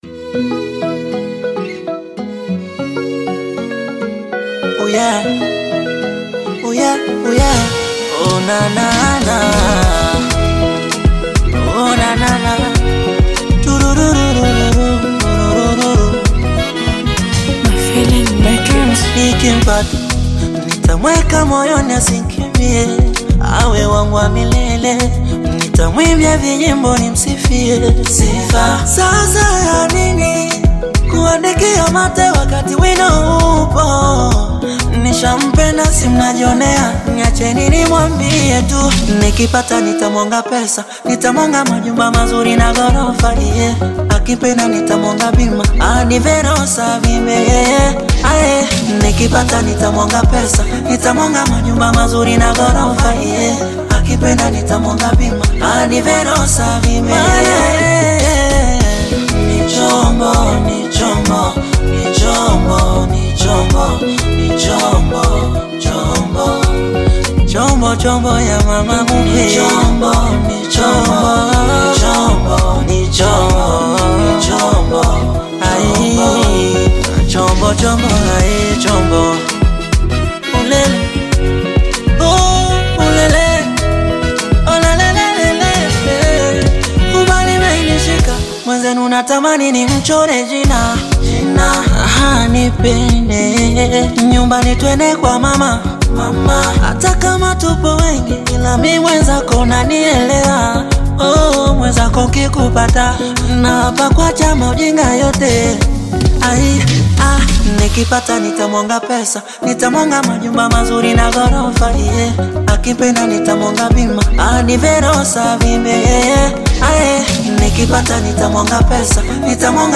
Bongo flava artist, singer and songwriter from Tanzania
African Music